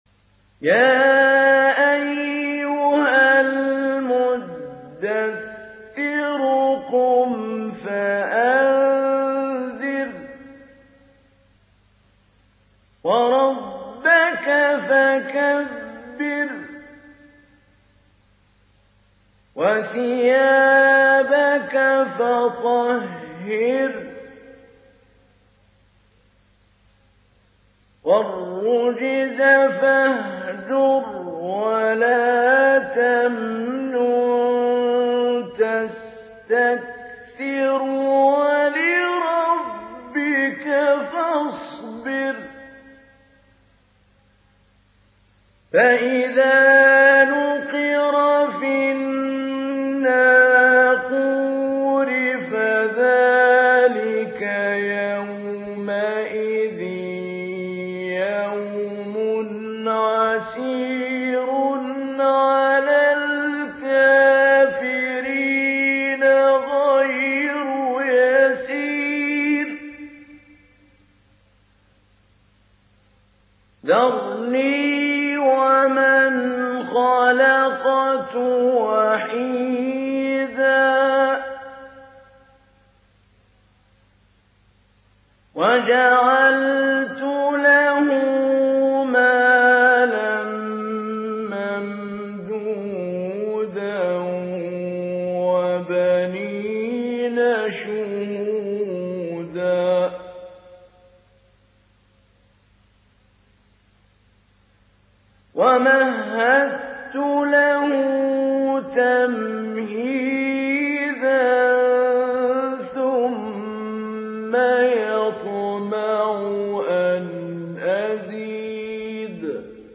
Download Surat Al Muddathir Mahmoud Ali Albanna Mujawwad